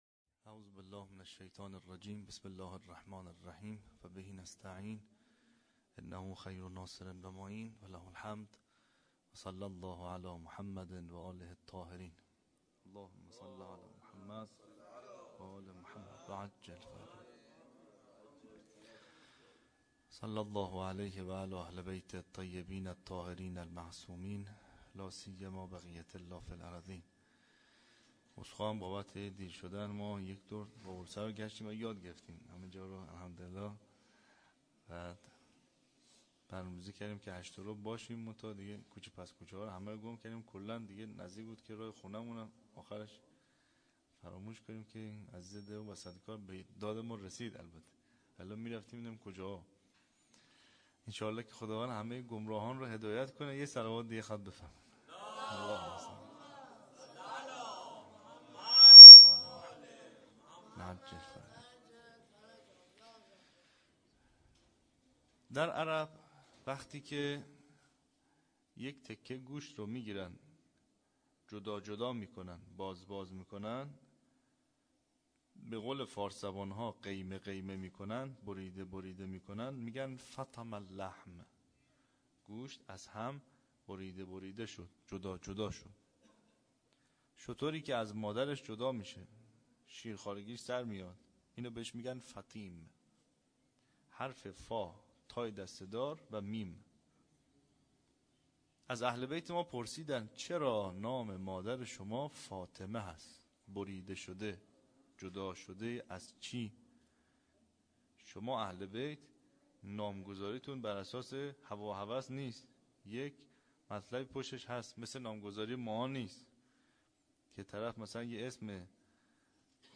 سخنرانی
شب سوم ویژه برنامه فاطمیه دوم ۱۴۳۹